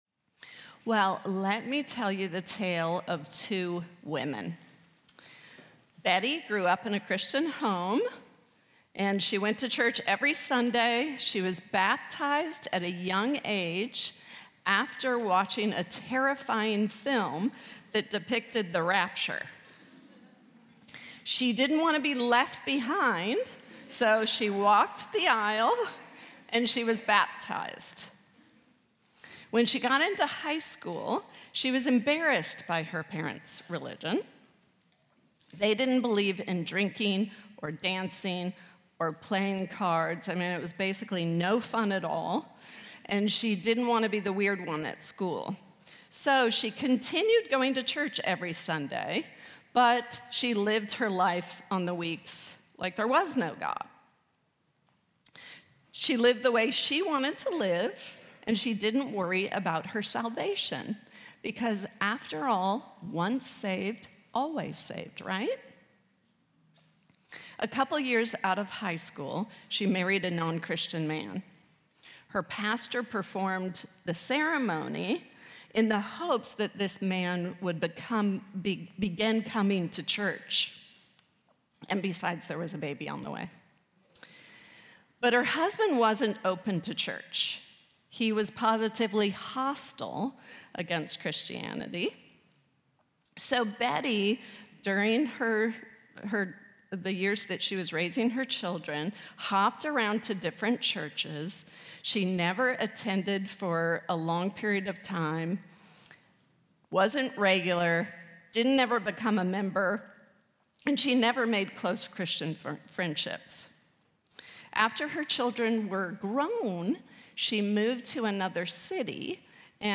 Audio recorded at MVBC’s 2025 Women’s Retreat.